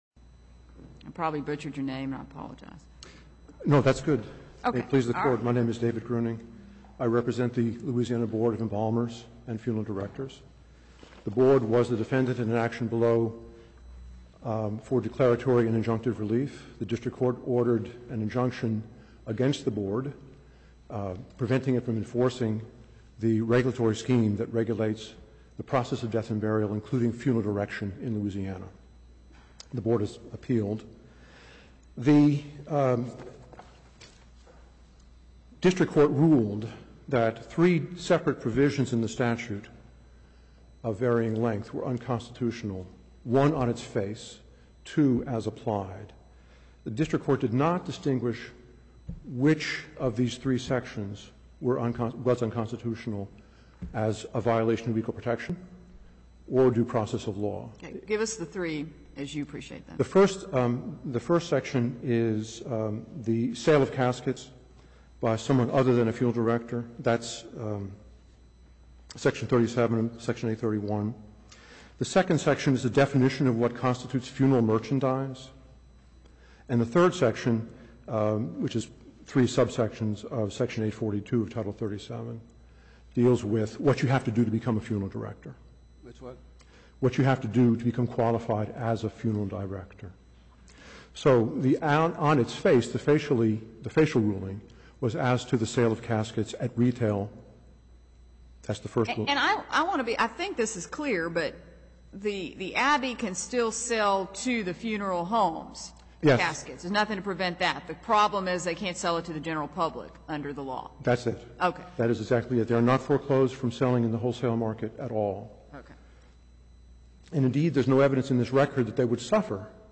The U.S. Court of Appeals for the Fifth Circuit has posted the audio of today’s oral argument at this link (24.6 MB Windows Media file).